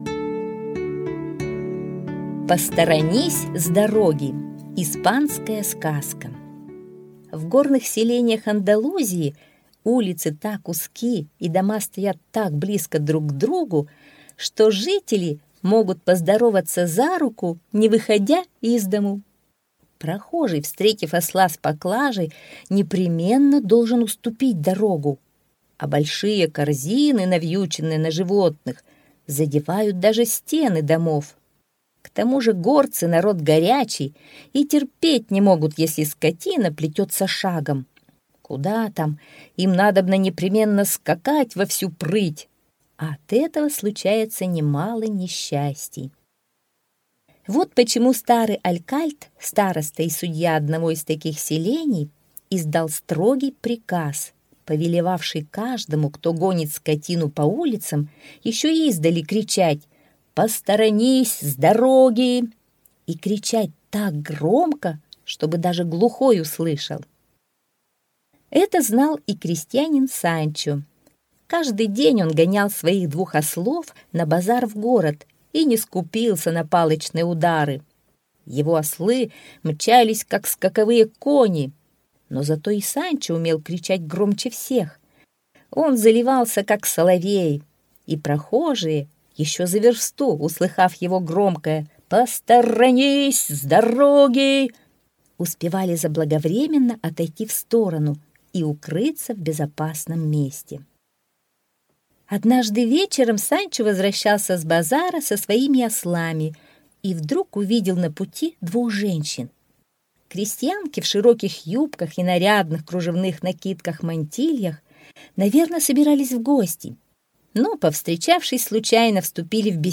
Аудиосказка «Посторонись с дороги!»